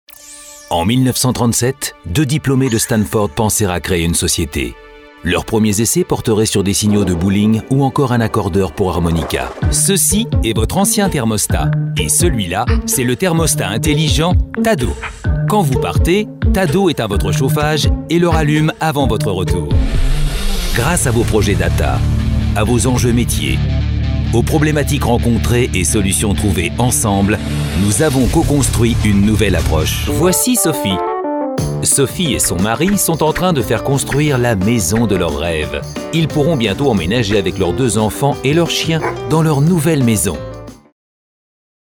Hello, I'm a french voice over with warm and deep voice for the narration. Sérious, reassuring and explanatory voice for corporate.
Sprechprobe: Industrie (Muttersprache):